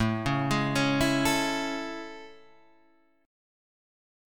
A Major 7th
AM7 chord {5 4 6 6 5 5} chord